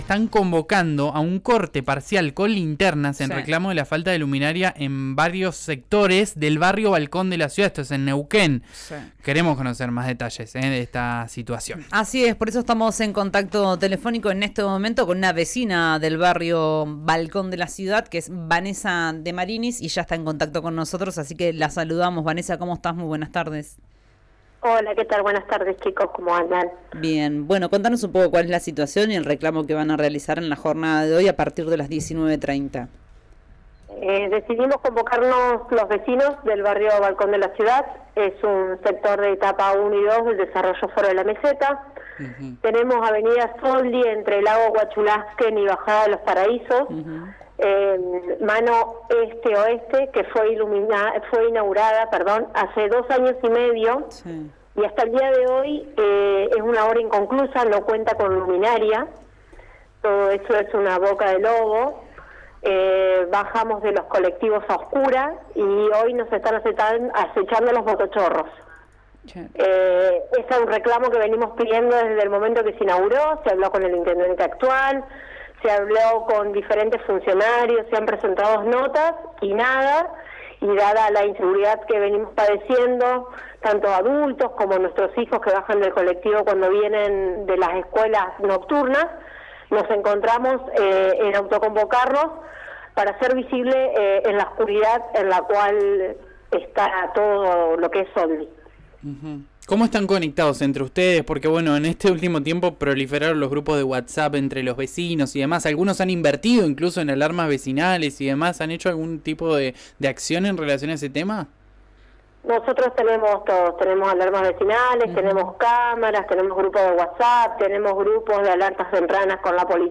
una de las vecinas afectadas en diálogo con RÍO NEGRO RADIO.